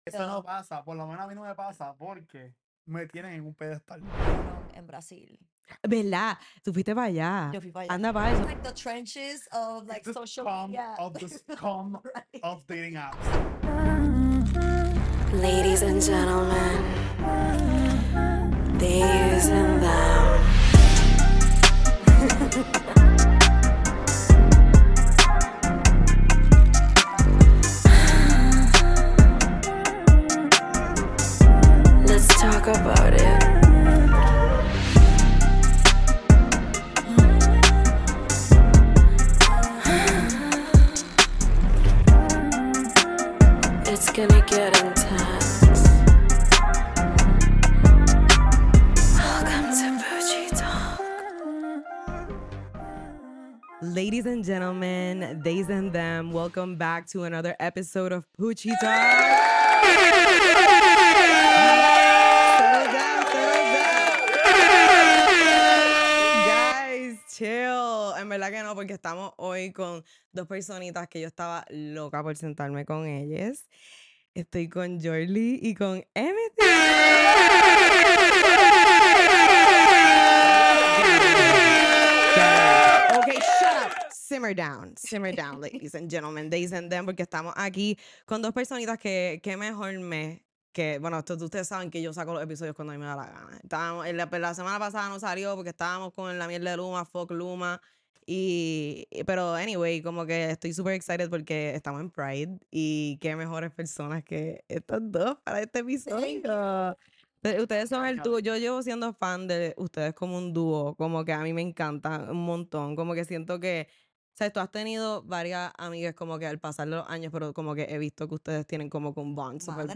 Entrevisté